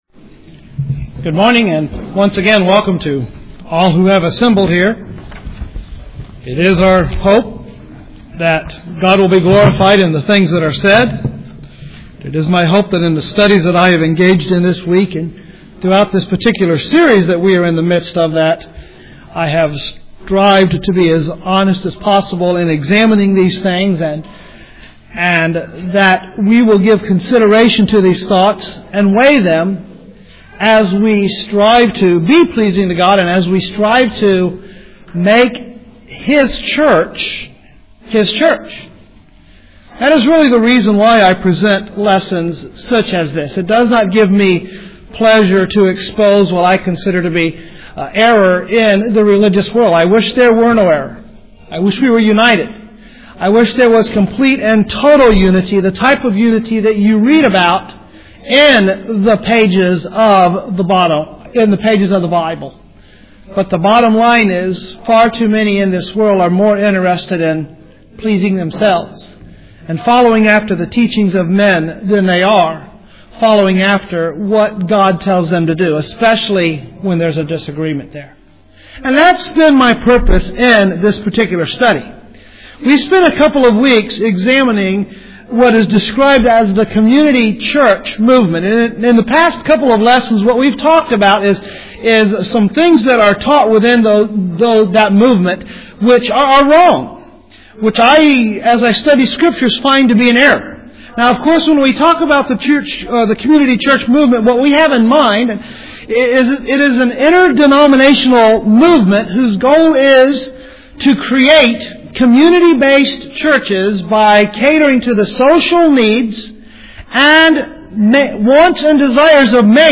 The following 4 sermons were presented in May and June, 2009 because of concerns over a growing movement that is a danger to the pure gospel of Christ.